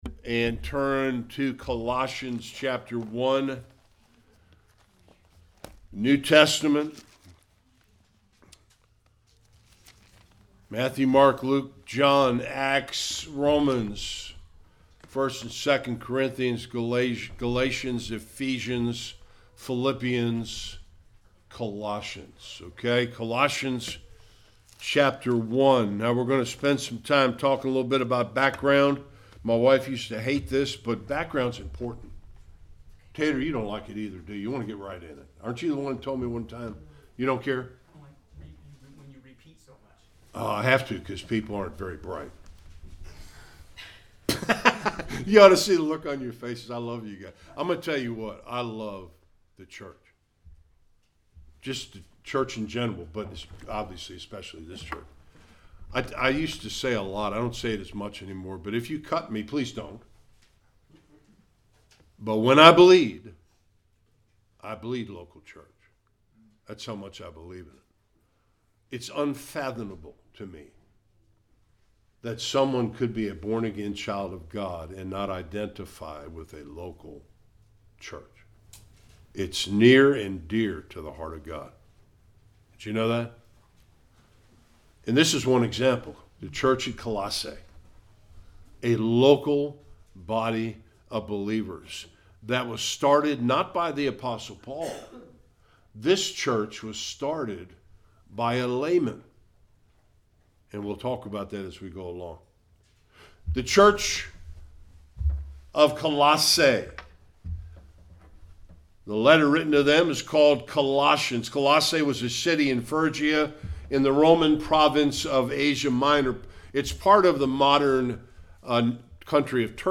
1-2 Service Type: Sunday Worship When and why Paul wrote Colossians